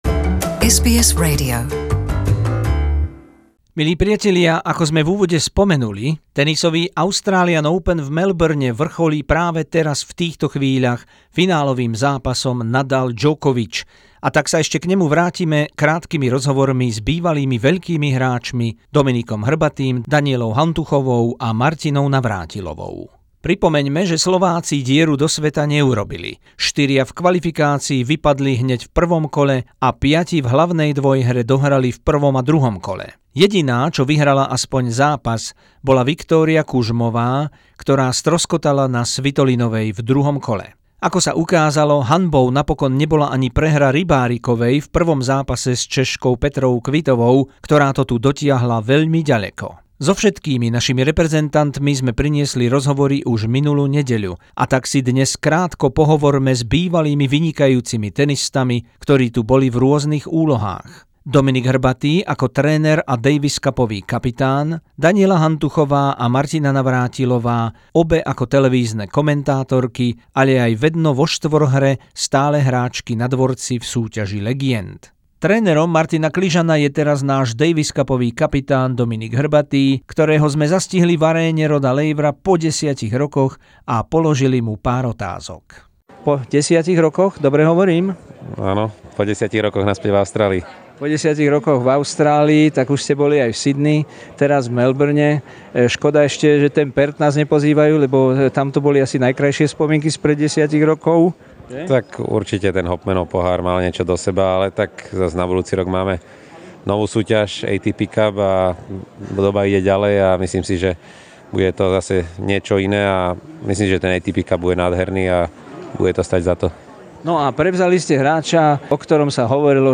Druhá reportáž z tenisových majstrovstiev Austrálie v Melbourne 2019, rozhovory s Danielou Hantuchovou, Martinou Navrátilovou a Dominikom Hrbatým.